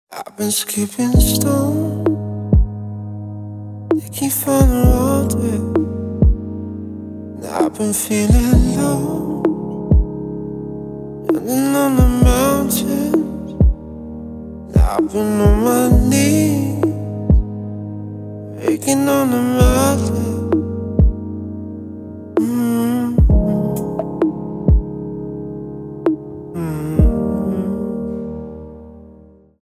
медленные , романтические , поп